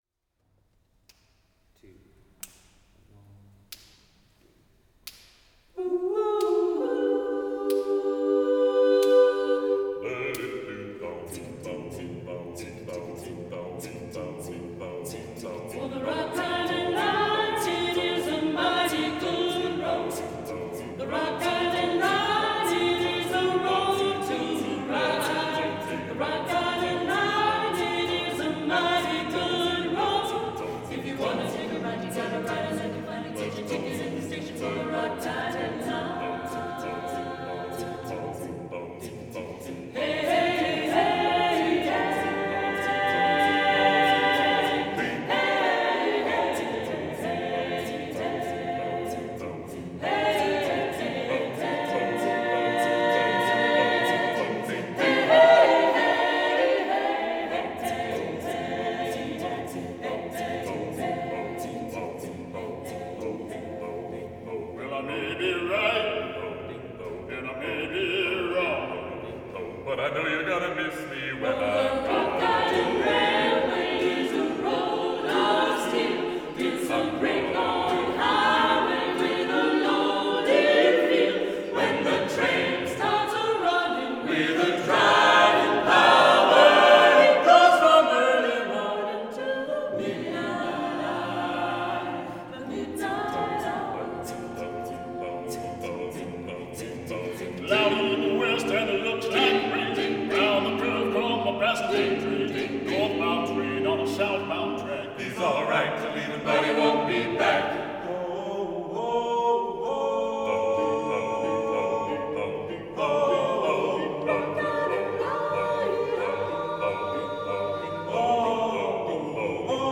A bluesy arrangement of a traditional railroad song.
SATB, AB soli, a cappella
Keep it hushed and moody.